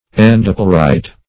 Search Result for " endopleurite" : The Collaborative International Dictionary of English v.0.48: Endopleurite \En`do*pleu"rite\, n. [Endo- + Gr.